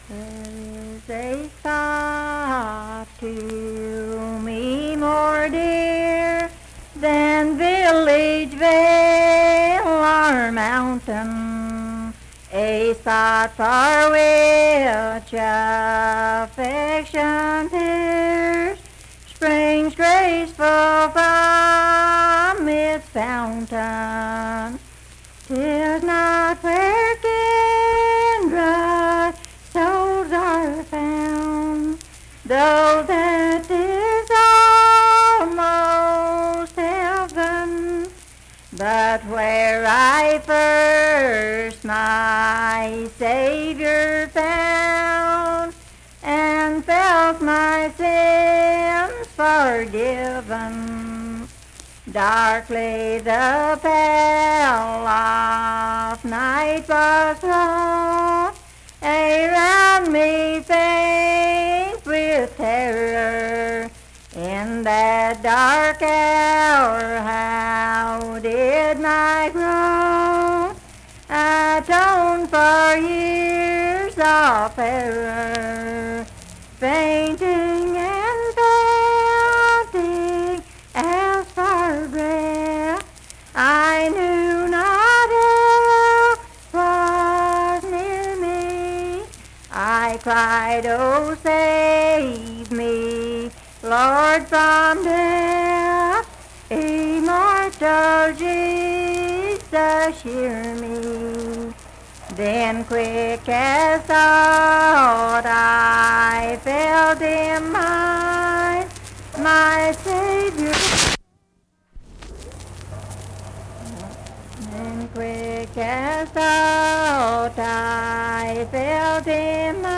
Unaccompanied vocal music
Hymns and Spiritual Music
Voice (sung)
Spencer (W. Va.), Roane County (W. Va.)